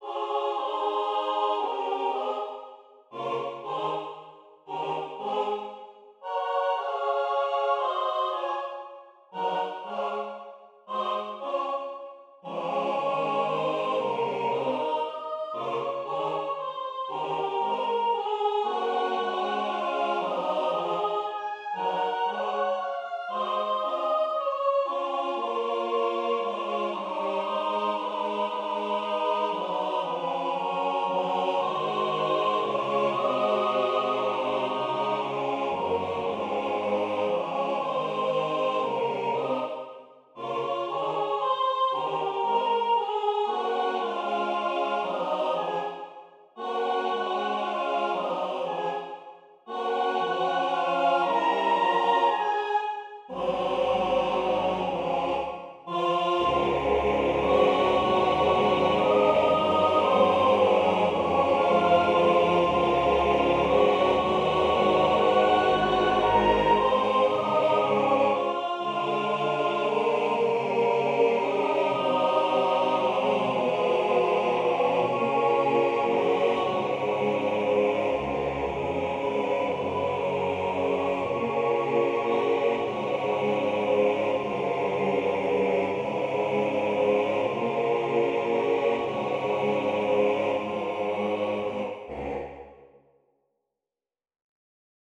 Key: C-sharp minor)
Совершенно классно звучит!
SATB: